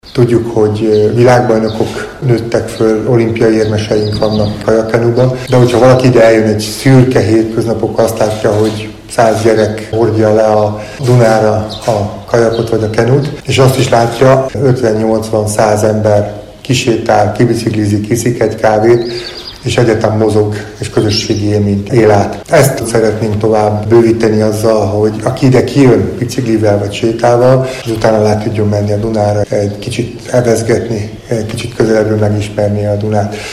Az ünnepélyes Viziturisztikai Központ átadóján Dr. Kovács Antal, az ASE elnöke, az MVM Paksi Atomerőmű Zrt. kommunikációs igazgatója, olimpiai bajnok cselgáncsozó az élsport és a közösségi sport fontosságát is hangsúlyozta.